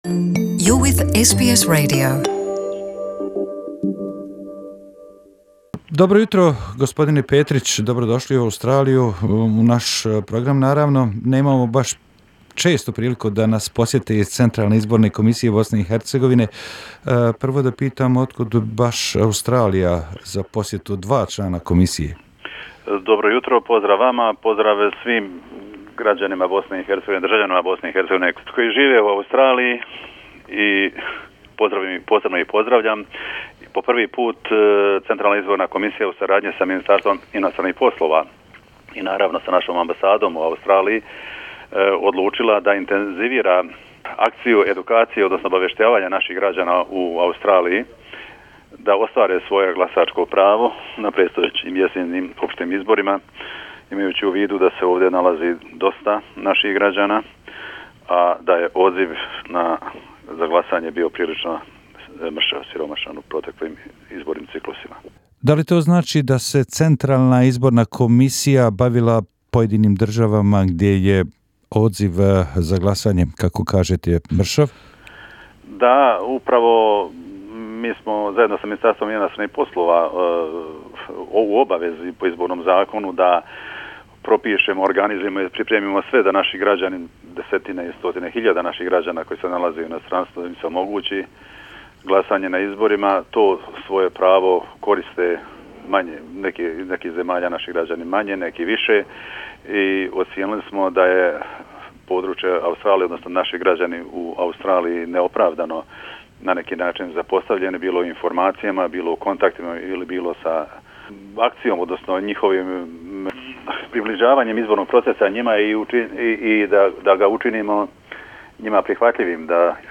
Branko Petric CIK BiH, Interview